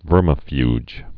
(vûrmə-fyj)